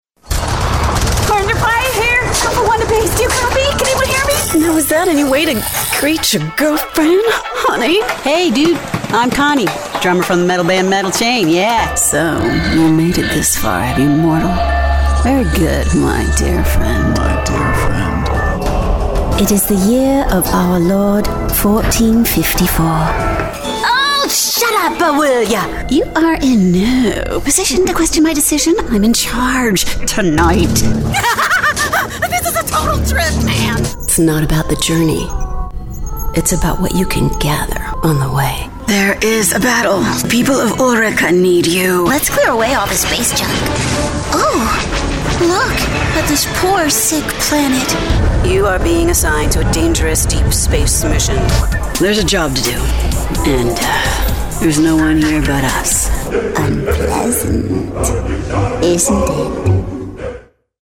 Female Voice Over, Dan Wachs Talent Agency.
Natural, Conversational, Best Friend, Employer.
Video Games